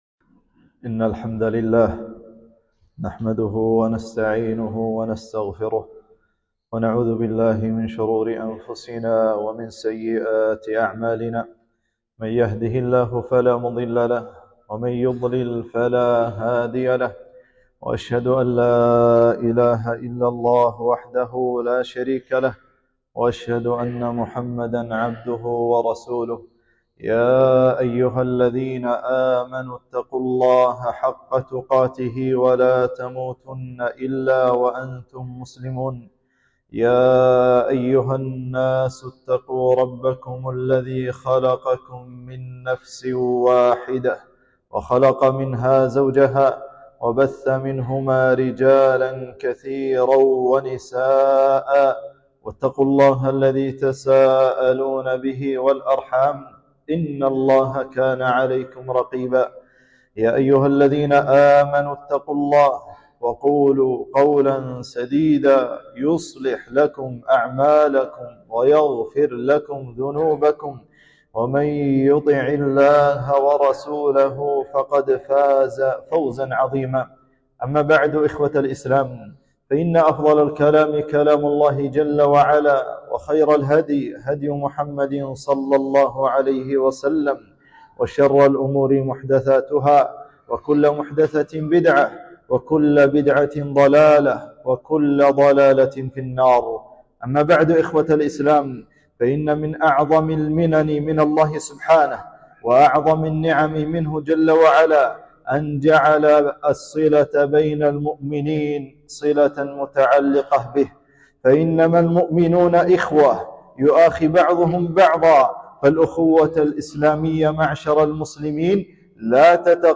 خطبة - ( إنما المؤمنون إخوة )